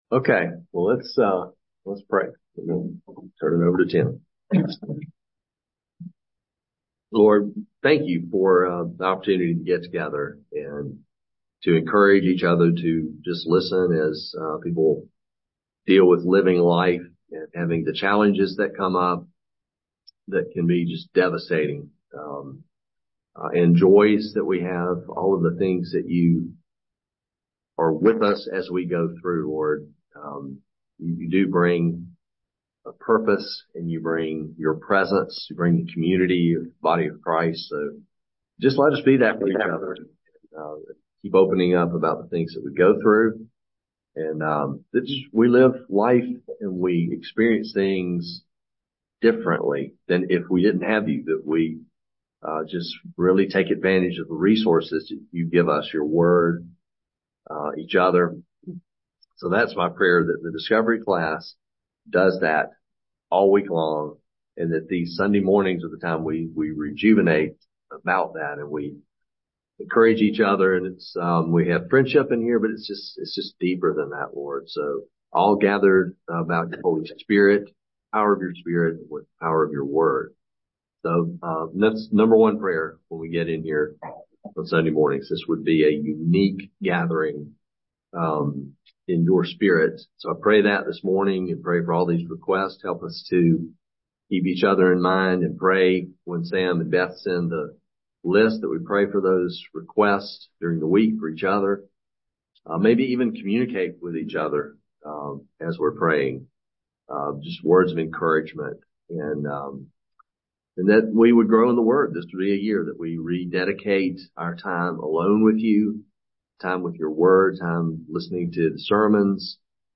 teaching on Matt 22:36-40.